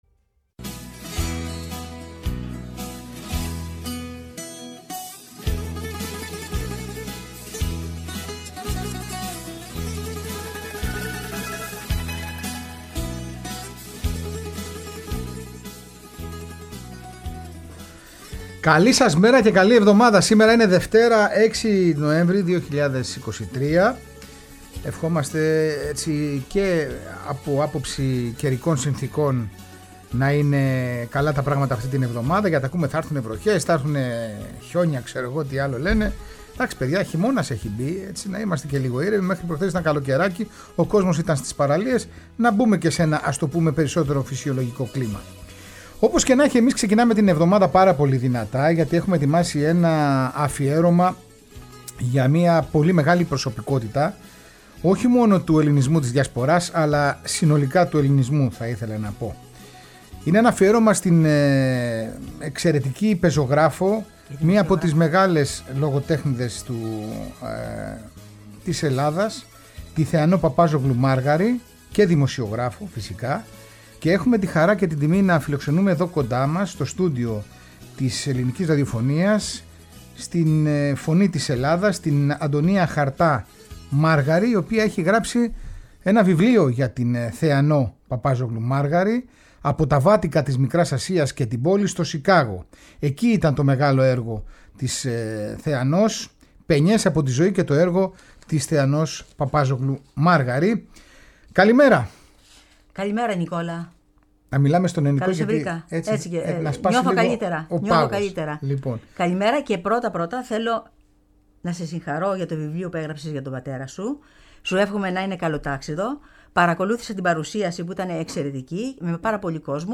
Ακούγεται η φωνή της σε ένα σπάνιο ηχητικό ντοκουμέντο και στιγμές από την πολυτάραχη και έντονη ζωή της που ήταν αφιερωμένη εξ’ ολοκλήρου στα ελληνικά γράμματα.
Συνεντεύξεις